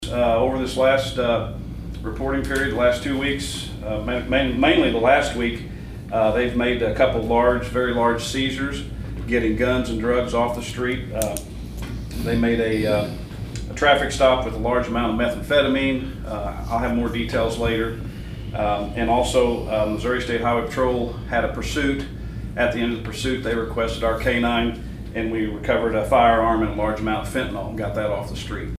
St. Francois Co., Mo. (KFMO) - St. Francois County Sheriff Jeff Crites gave a report to the county commission on Tuesday, including a report on the latest numbers reported by his department.